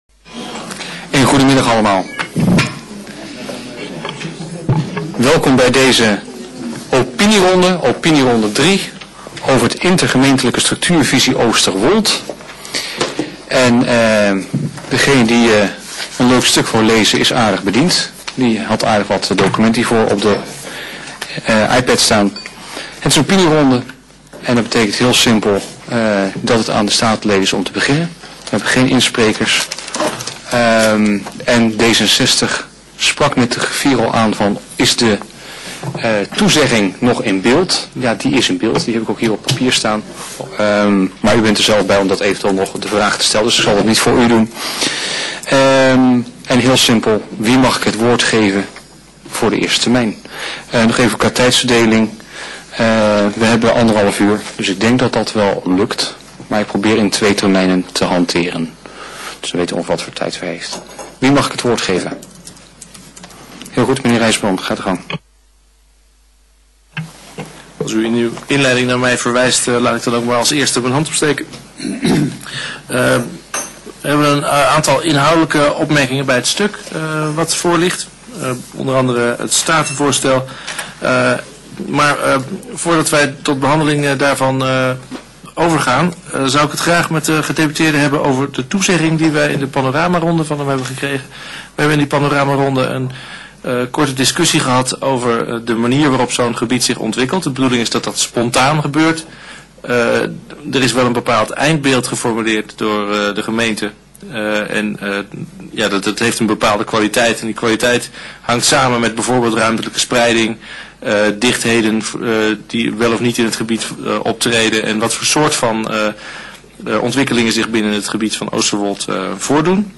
Locatie: Statenzaal